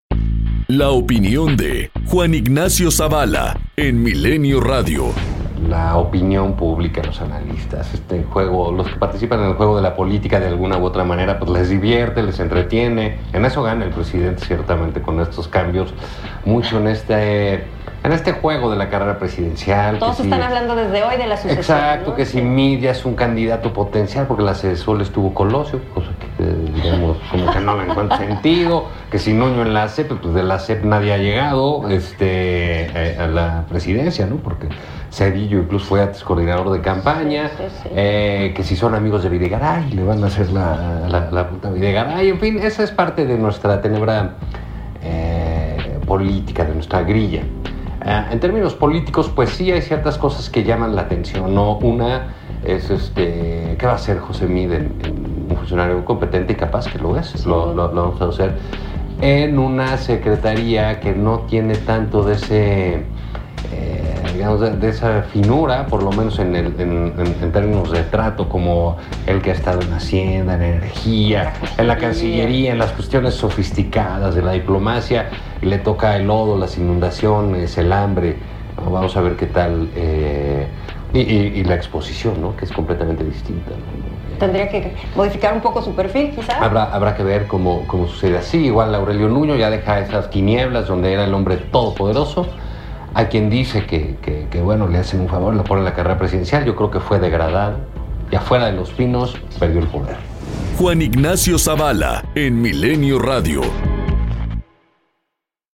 Comentario